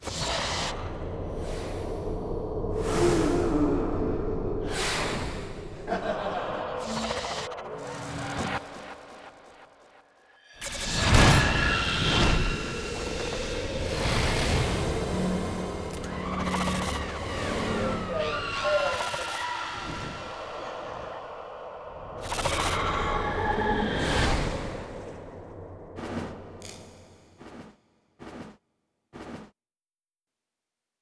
FA Marathon_Starting Next Monday30_ST SFX.wav